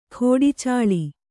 ♪ khōḍicāḷi